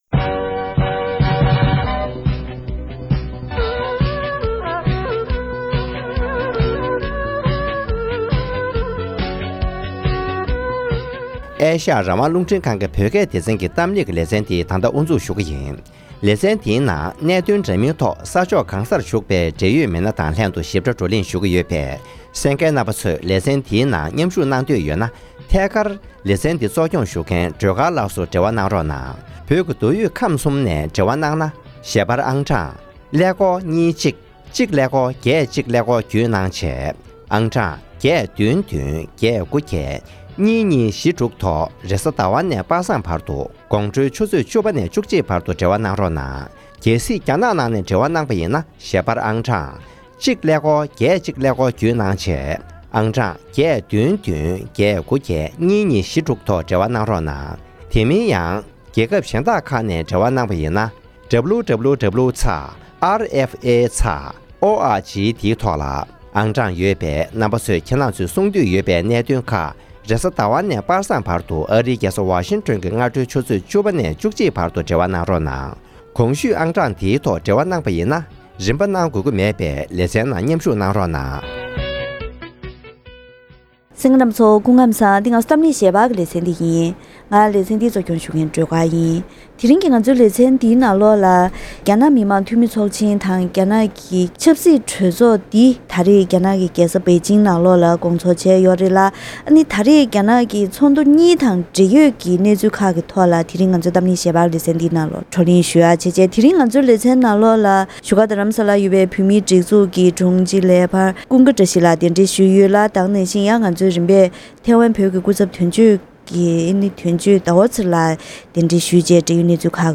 ༄༅། །ཐེངསའ་འདིའི་གཏམ་གླེང་ཞལ་པར་གྱི་ལེ་ཚན་ནང་དུ།